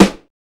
• Hot Snare Sound G Key 434.wav
Royality free snare drum sample tuned to the G note. Loudest frequency: 979Hz
hot-snare-sound-g-key-434-PqO.wav